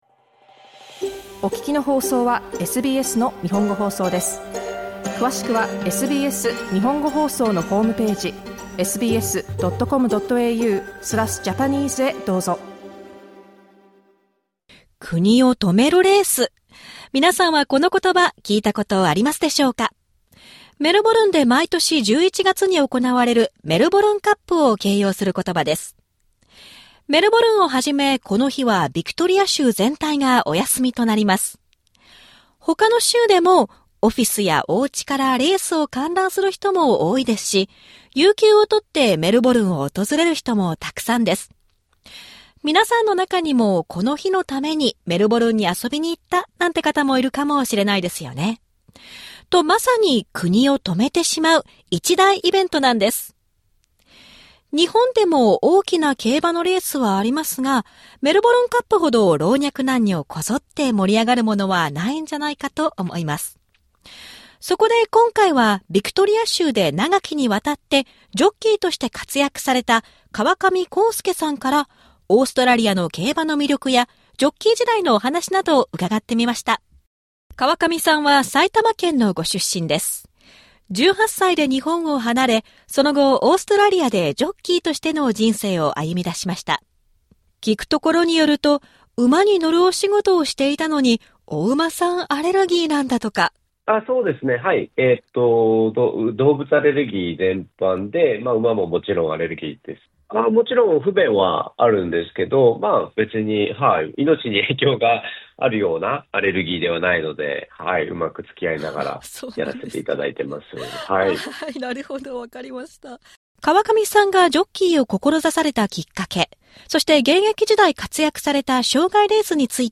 2024年放送。